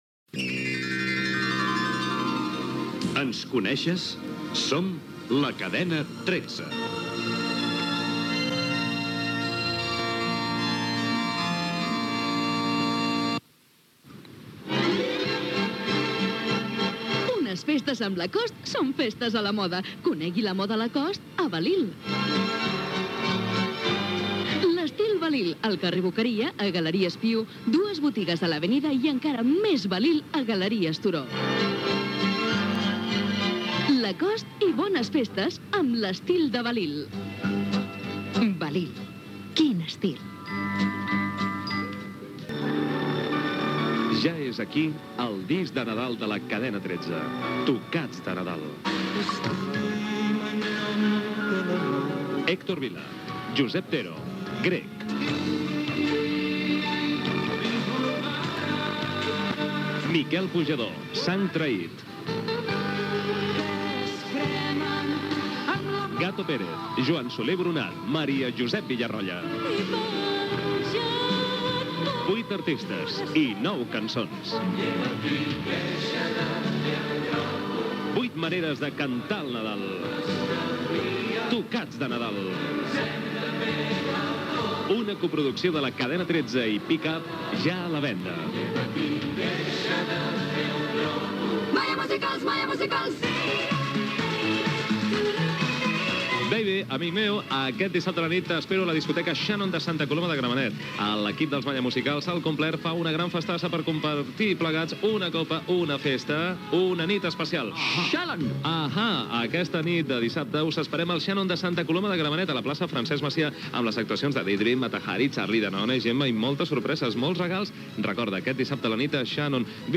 Indicatiu de la cadena, publicitat, disc de Nadal de la Cadena 13
FM